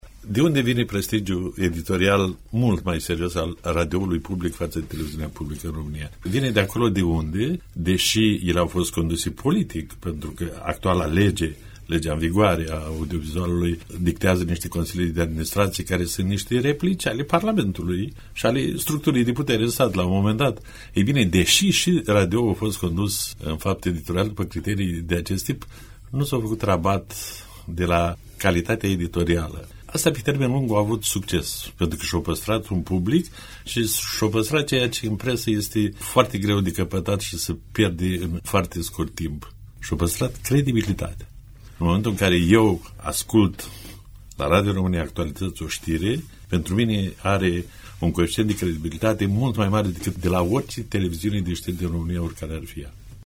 Propunerea de modificare a Legii 41, de organizare şi funcţionare a societăţilor publice de radio şi televiziune, a fost, astăzi, subiect de dezbatere la Radio Iaşi.